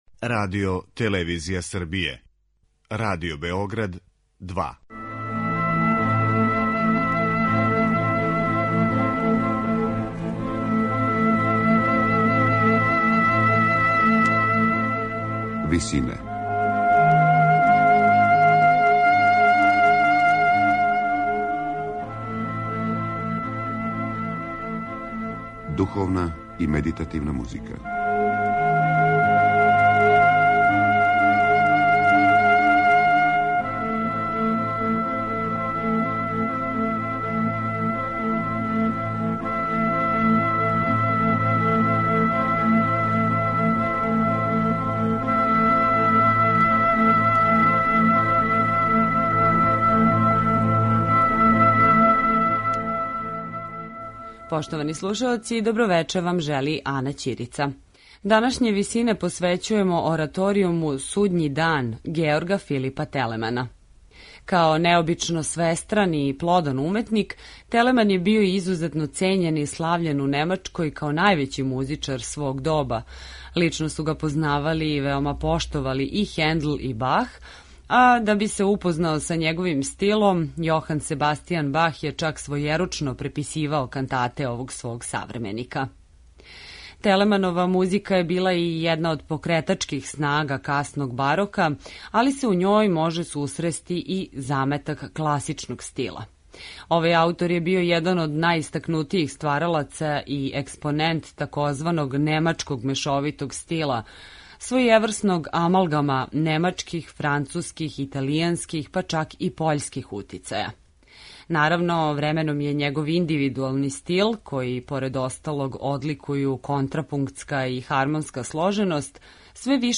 Ораторијум Судњи дан
Остварење „Судњи дан" из 1762. године, написано за солисте, хор, оркестар и континуо, представља последњи Телеманов ораторијум у којем се највише огледају утицаји Георга Фридриха Хендла.